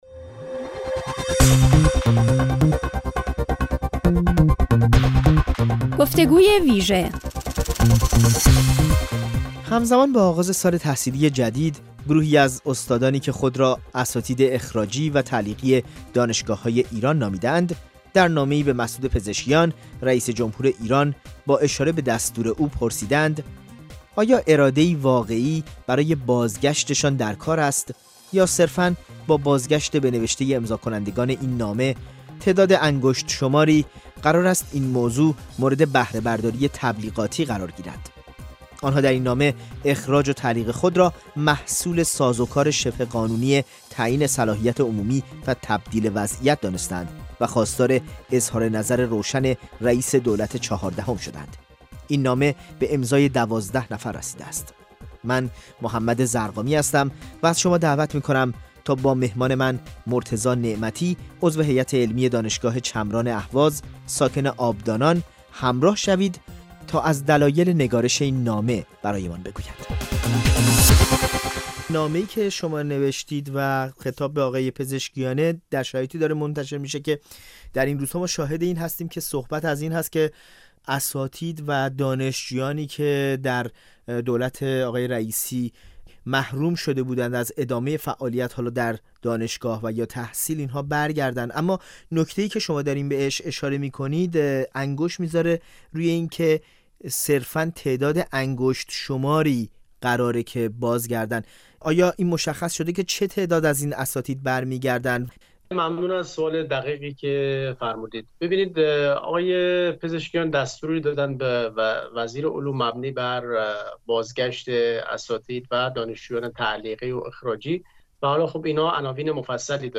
با حضور در برنامه گفت‌وگوی ویژه رادیوفردا به پرسش‌هایی درباره آخرین وضعیت این گروه از اساتید پاسخ داده است